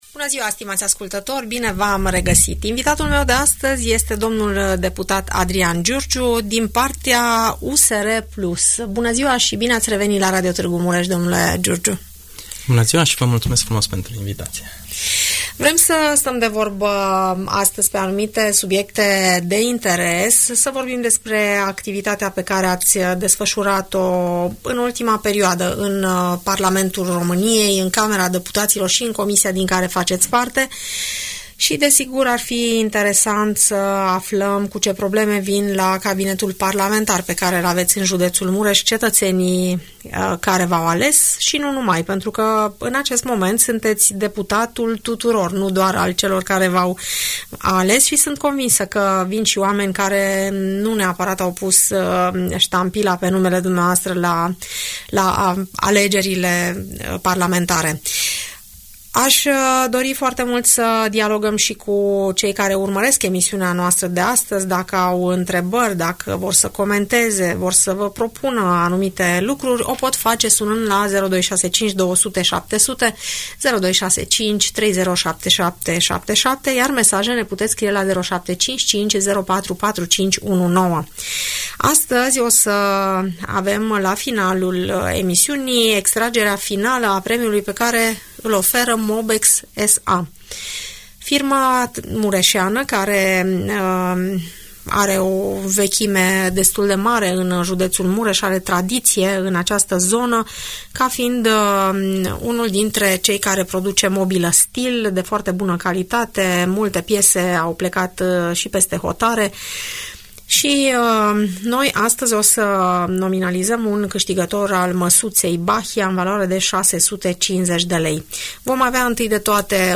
Deputatul Adrian Giurgiu membru al Uniunii Salvați România,vorbește despre preocupările sale și răspunde la întrebările ascultătorilor Radio Tg Mureș.